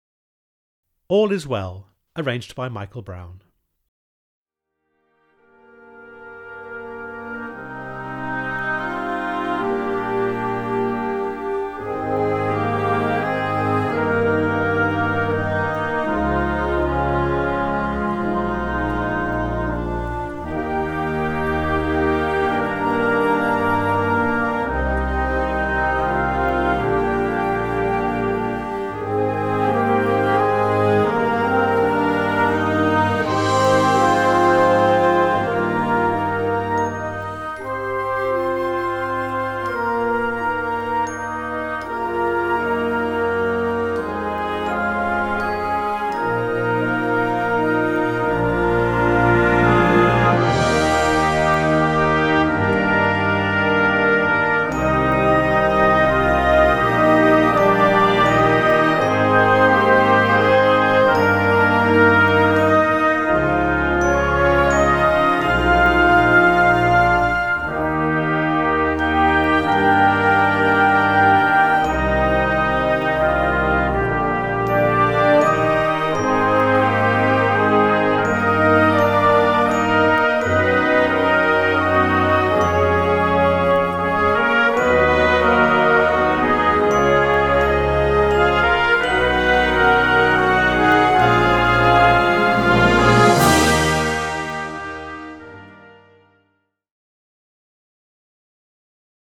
Besetzung: Blasorchester
Eine Atmosphäre der Ruhe und des Friedens